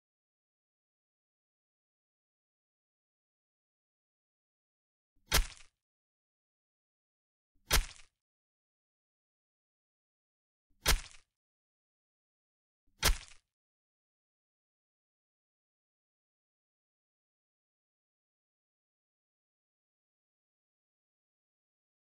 دانلود آهنگ چاقو زدن 3 از افکت صوتی اشیاء
جلوه های صوتی
دانلود صدای چاقو زدن 3 از ساعد نیوز با لینک مستقیم و کیفیت بالا